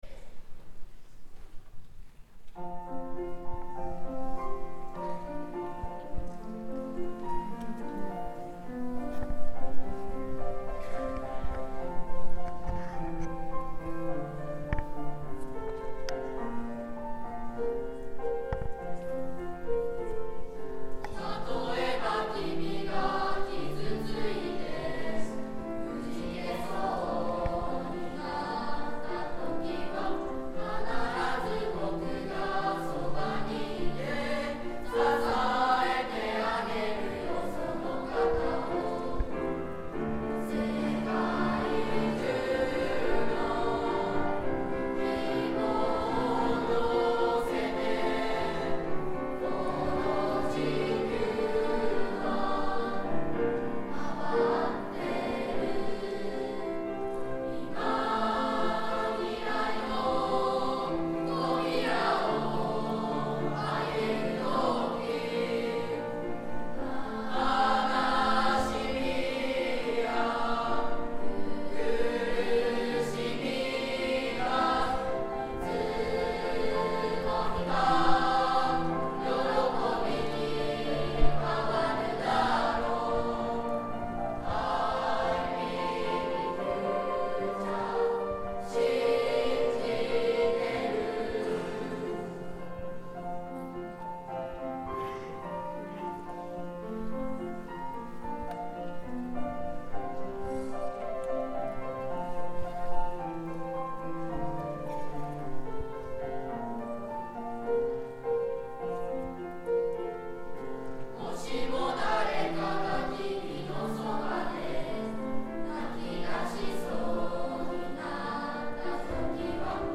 １Ｇ Believe.mp3←クリックすると合唱が聴けます